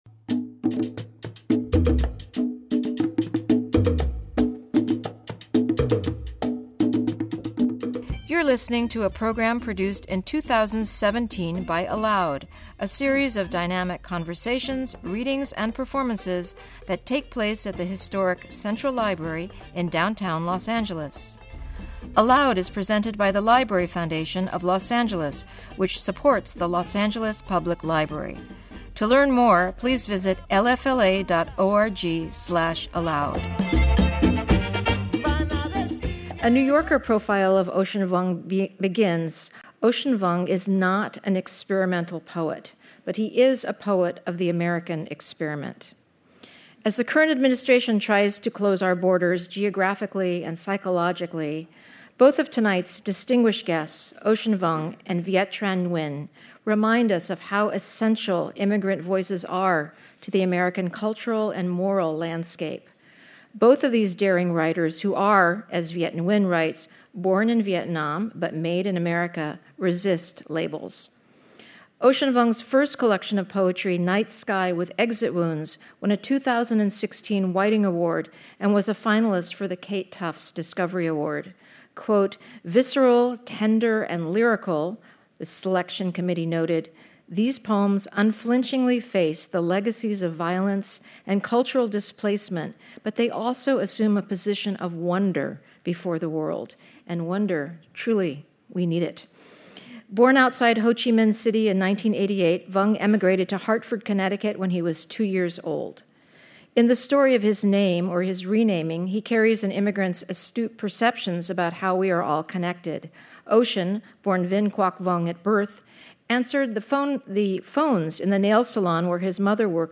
Ocean Vuong In Conversation With Author Viet Thanh Nguyen
Reflecting on how geographical and linguistic energies intersect and what it means to write as a Vietnamese refugee in the contemporary space, Vuong reads from and discusses his poetry with Pulitzer Prize-winning novelist Viet Thanh Nguyen, whose writing also often explores the Vietnamese American experience.